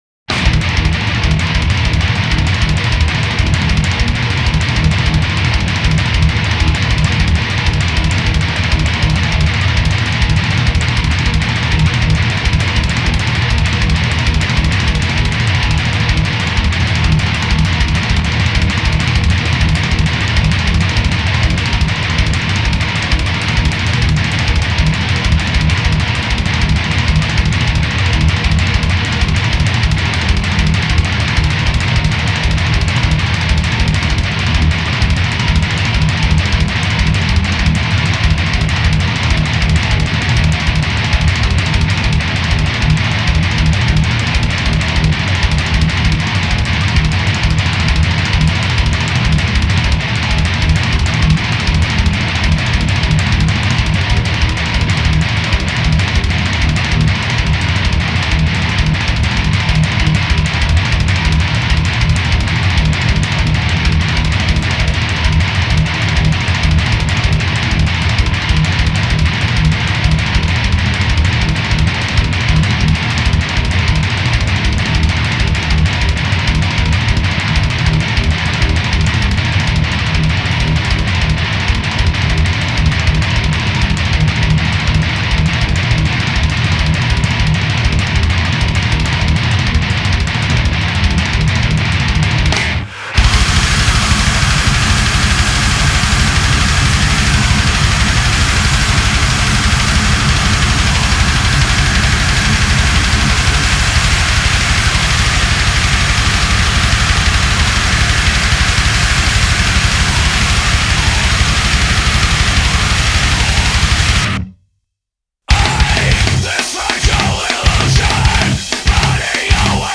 Extreme Progressive Metal, Djent